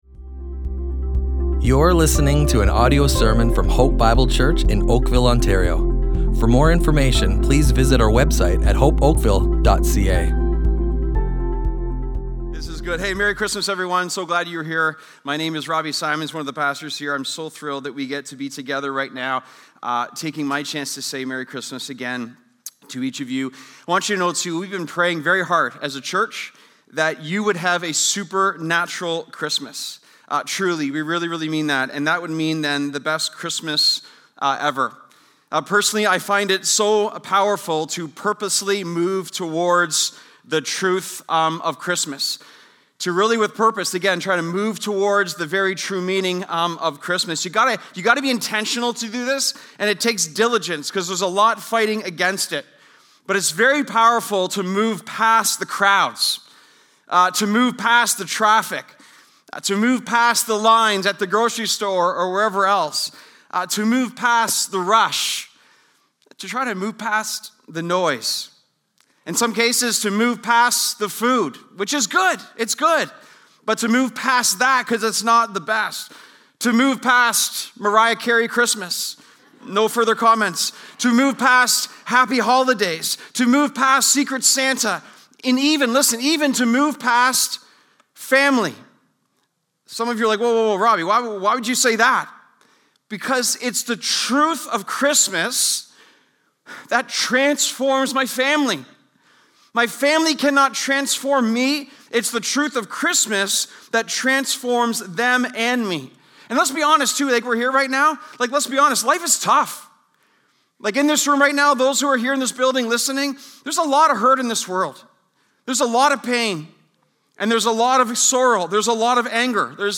Hope Bible Church Oakville Audio Sermons Christmas Eve 2025 // The Truth of Christmas!